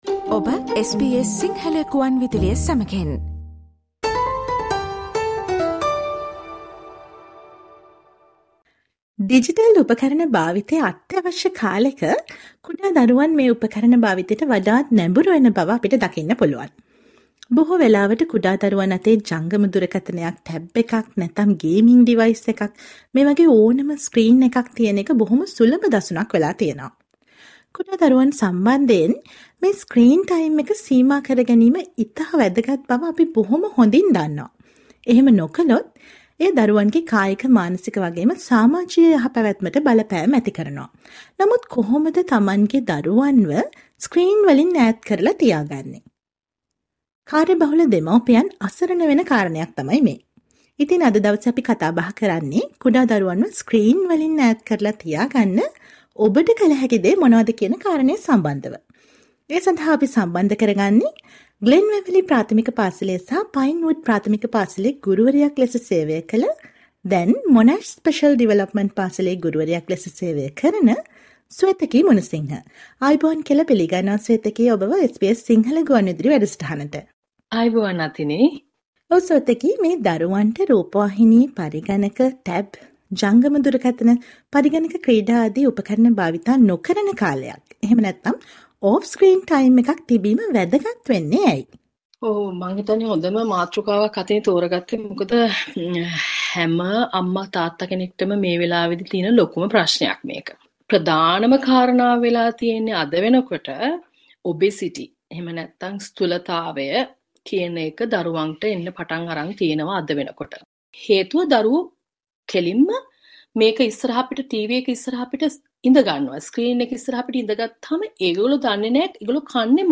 සවන් දෙන්න මේ ගුවන් විදුලි විශේෂාංගයට